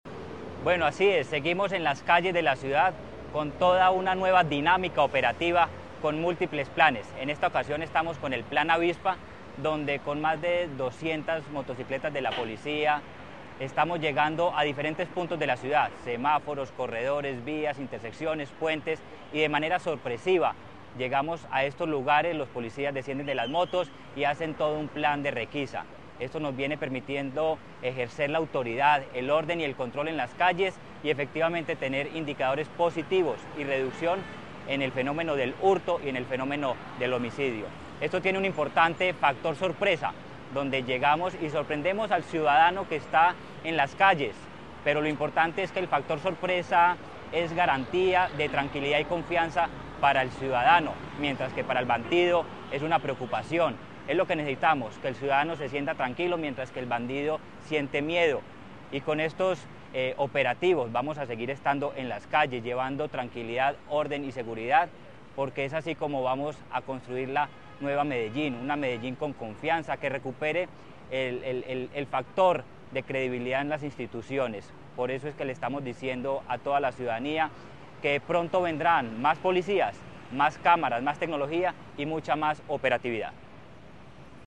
Palabras de Manuel Villa Mejía, secretario de Seguridad y Convivencia La Alcaldía de Medellín y la Policía Metropolitana articulan esfuerzos para fortalecer la seguridad ciudadana mediante la estrategia denominada «Plan Avispa».